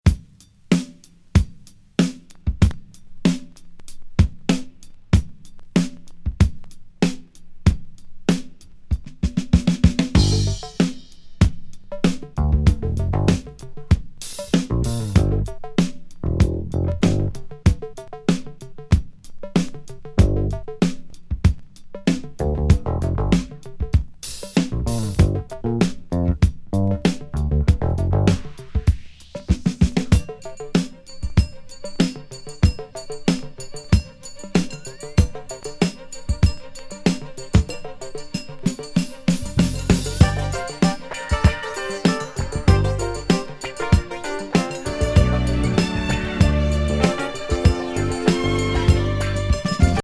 悪魔尺八シンセ・ディスコ
ドラム・ブレイクから始まる
不穏ファンク